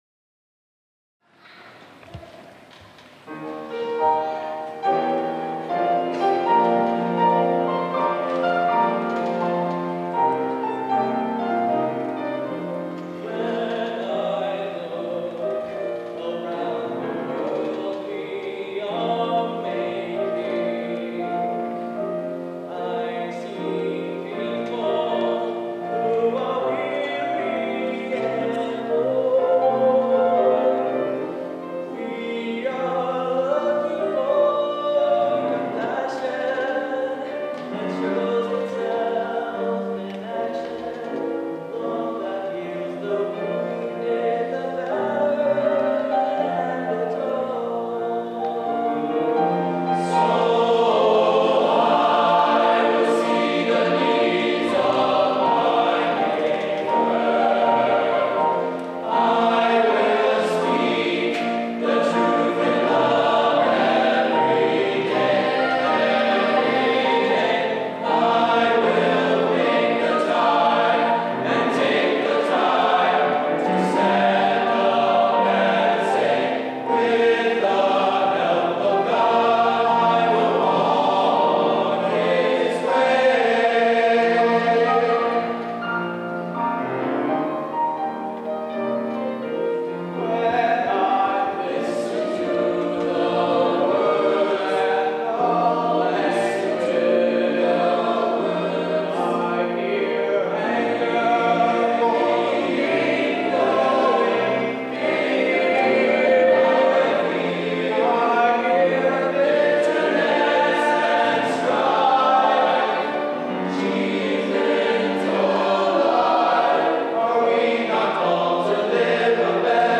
Voicing: 2-Part Treble